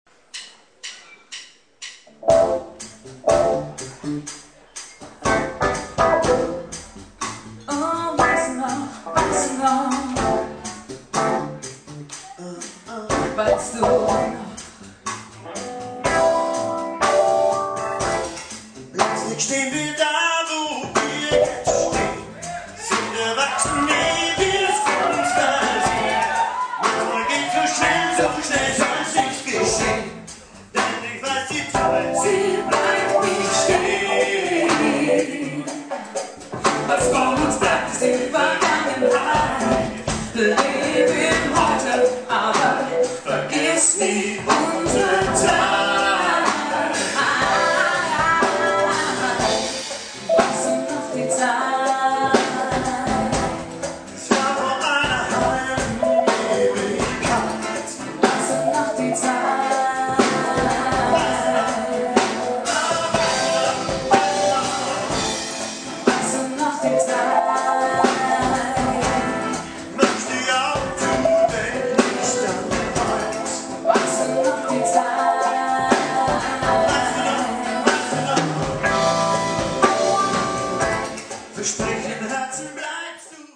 Live-Mitschnitte:
akustischen Gitarre